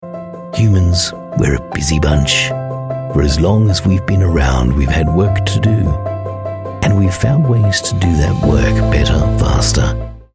Explainer Videos
Neumann TLM 103 mic